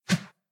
initial hit sounds
whoosh1.ogg